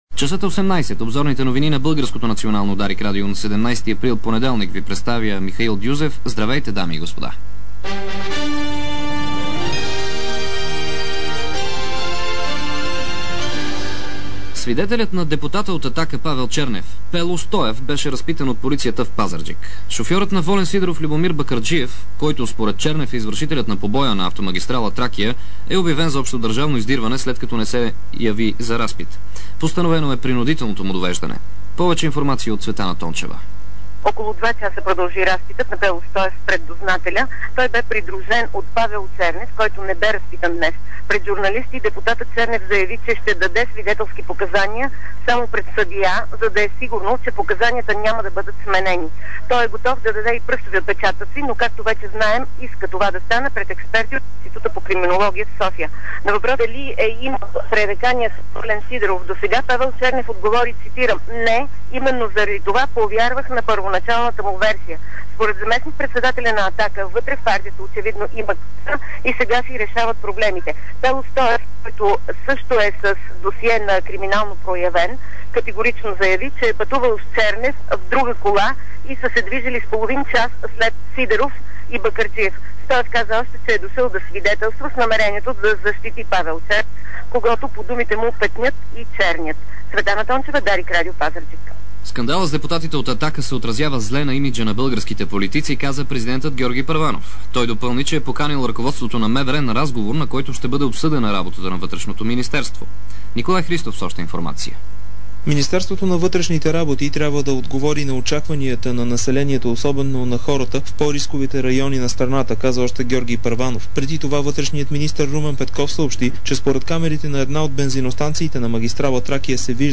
DarikNews audio: Обзорна информационна емисия 17.04.2006